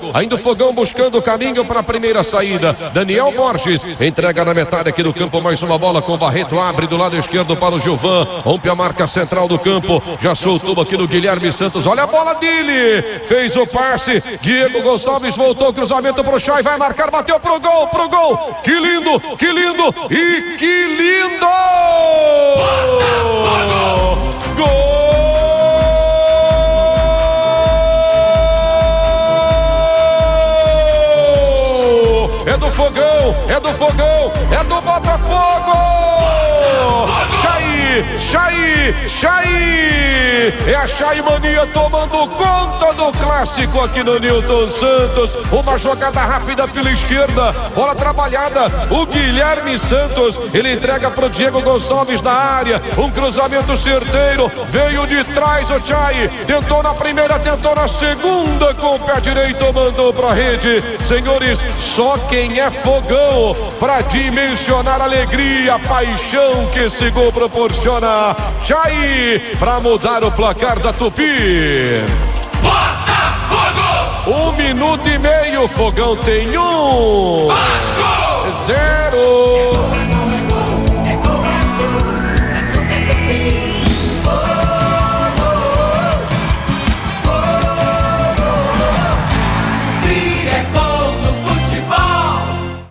Chay e Diego Gonçalves marcaram para o glorioso no Estádio Nilton Santos